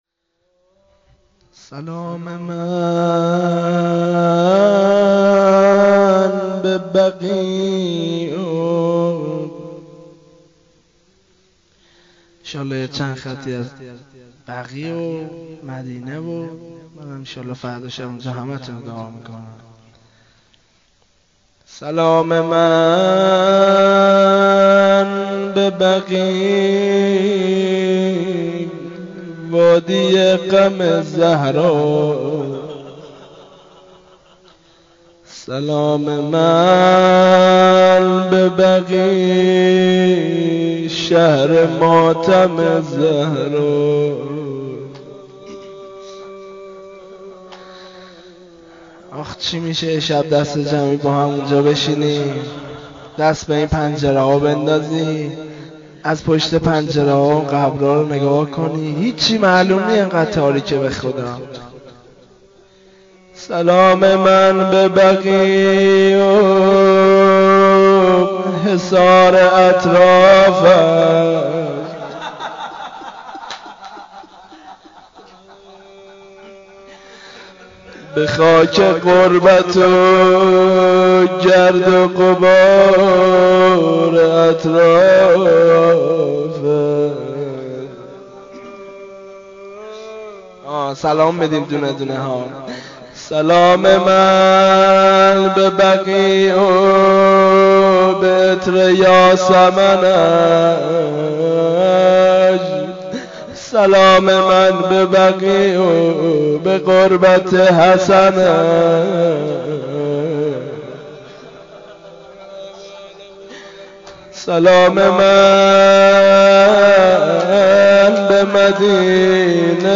روضه
روضه‌ی خانگی